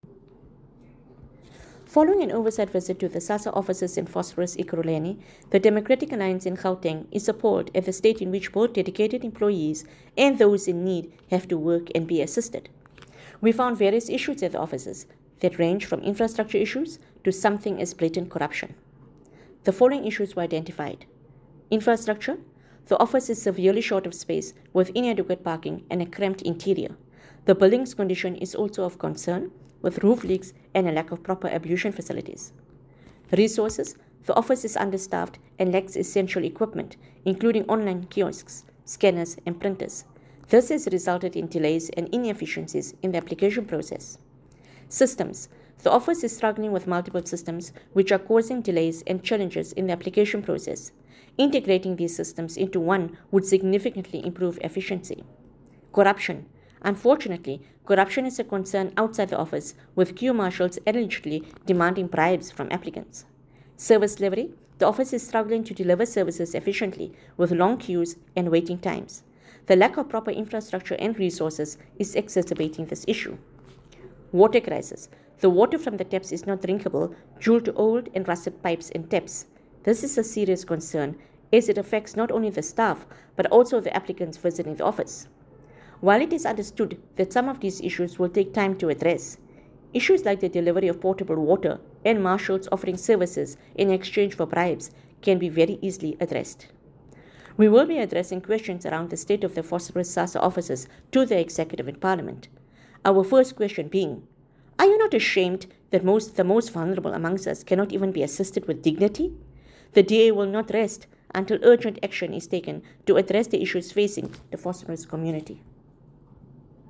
Note to Editors: Please find a soundbite by Haseena Ismail MP